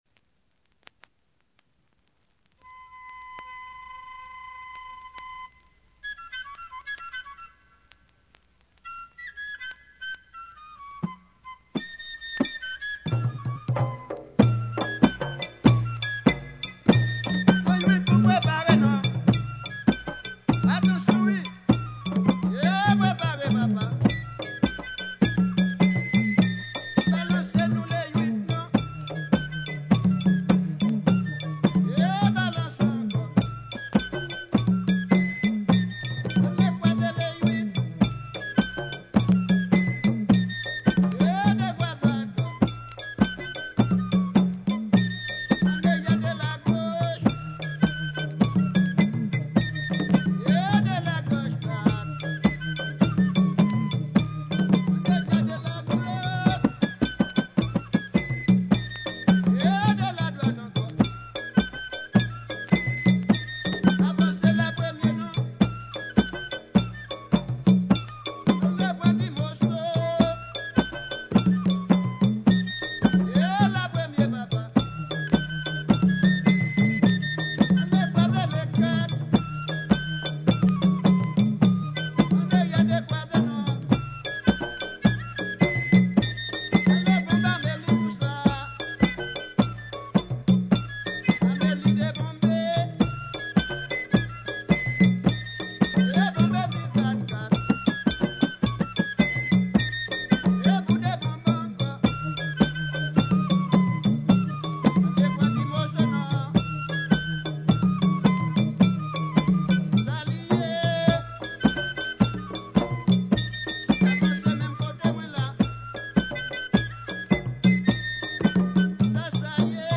2022 CONTRE DANSE (VIEUX GRIS) FLOKLORE HAITIEN audio closed https